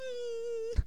hummm
Category 🗣 Voices
male meme sweetness vocalization sound effect free sound royalty free Voices